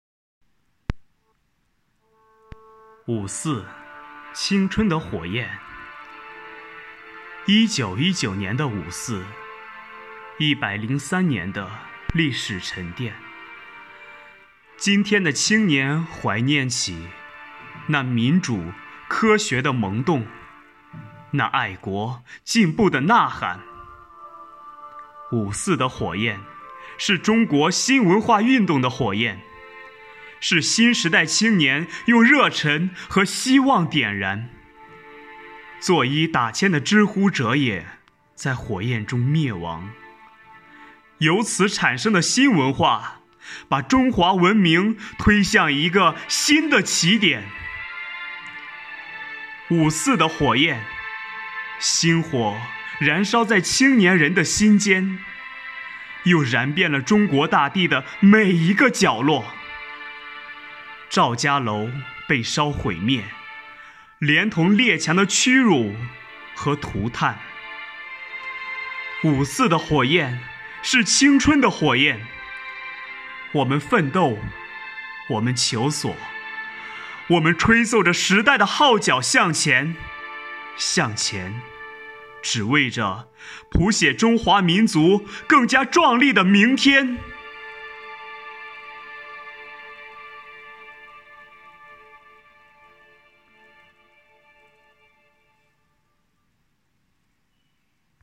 在五一劳动节、五四青年节来临之际，市纪委监委宣传部、机关党委组织青年干部，以“奋进新征程筑梦新时代”为主题，以朗诵为载体，用诗篇来明志，抒发对祖国的热爱、对梦想的执着、对青春的礼赞。